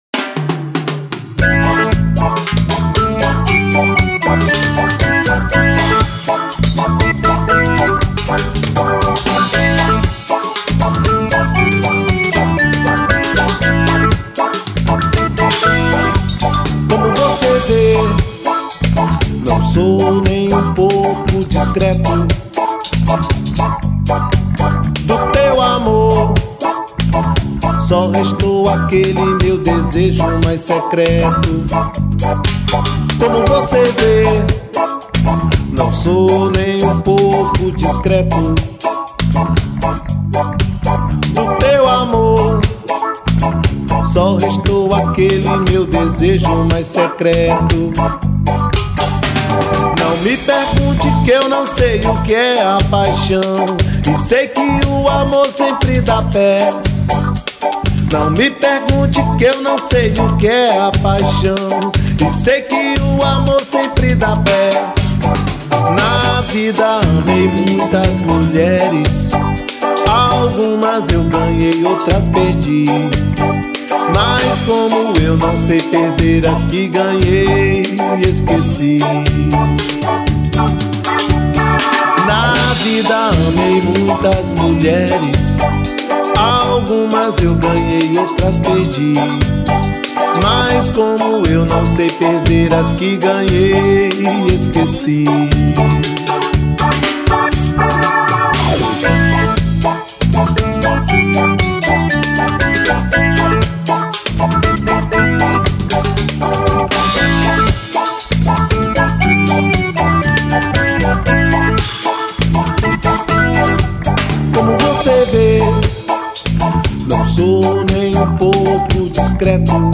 1342   03:54:00   Faixa:     Rock Nacional